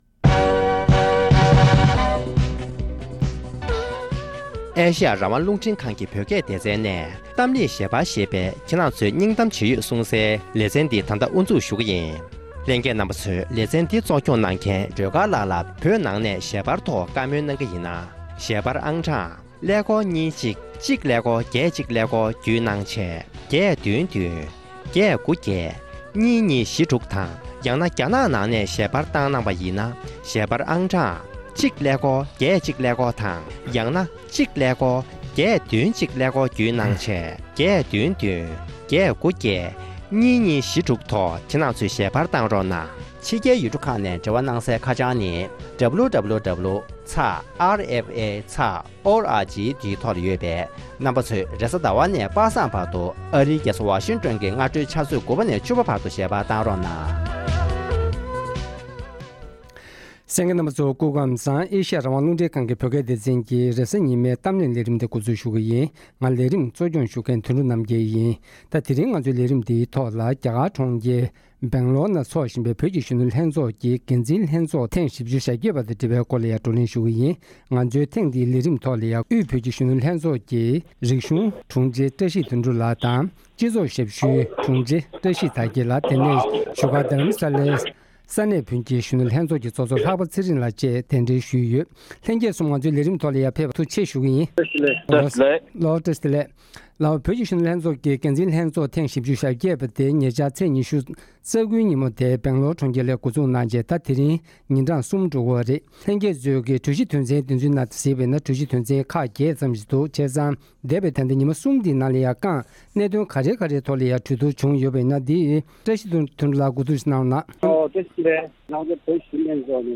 གཞོན་ནུ་ཚོགས་བཅར་བ་ཁག་དང་བགྲོ་གླེང་ཞུས་པ།